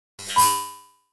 جلوه های صوتی
دانلود صدای ربات 16 از ساعد نیوز با لینک مستقیم و کیفیت بالا